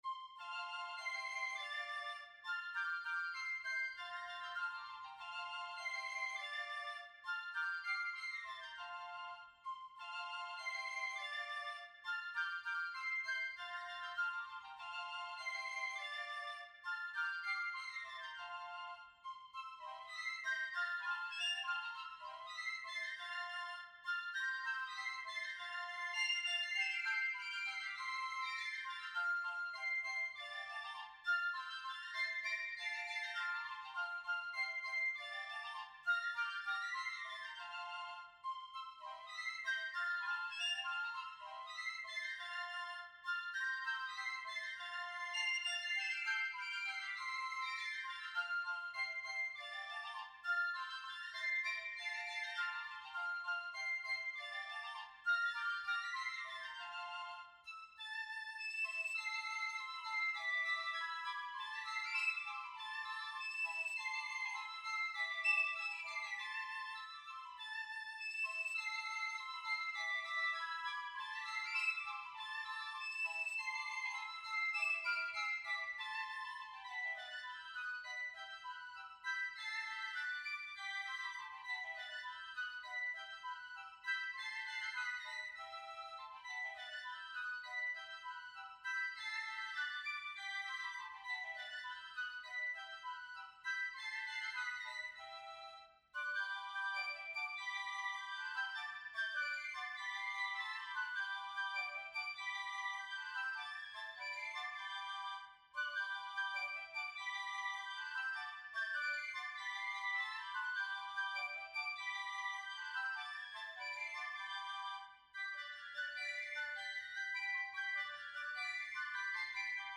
Eigene Kompositionen und Arrangements für Basler Piccolo
Marsch in Rondo-Form für vier Piccoli.